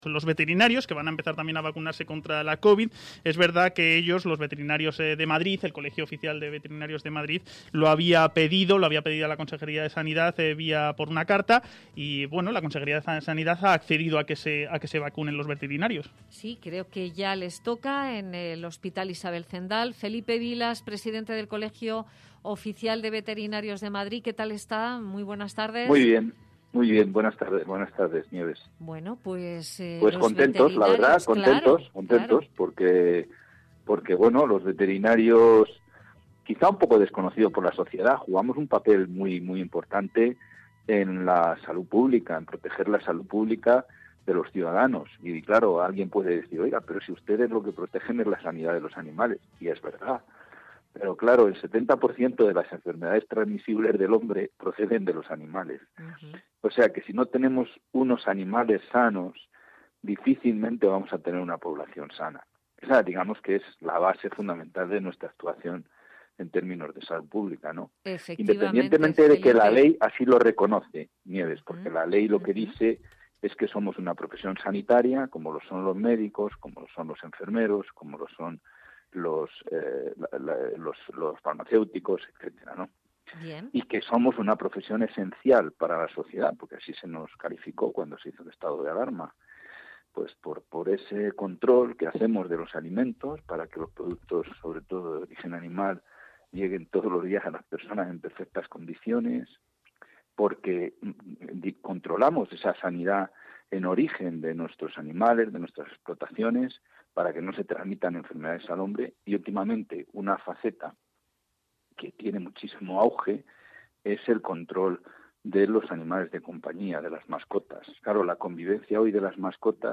una entrevista